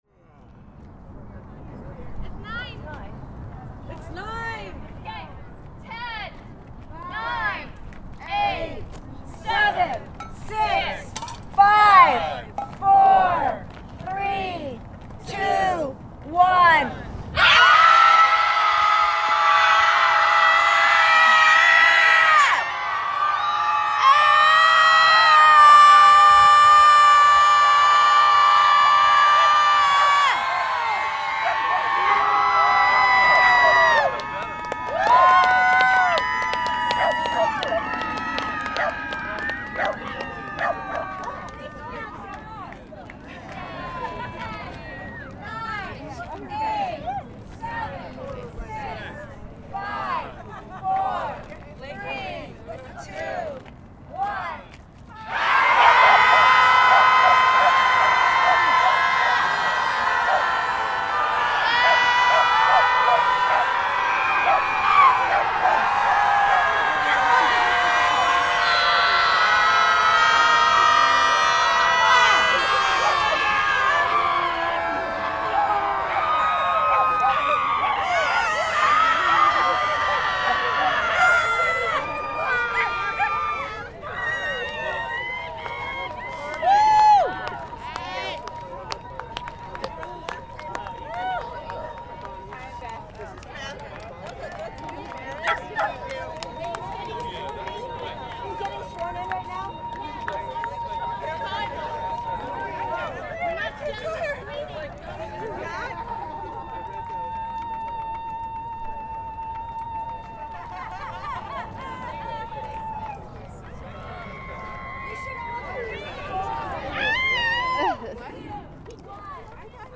A podcast where audio-makers stand silently in fields (or things that could be broadly interpreted as fields).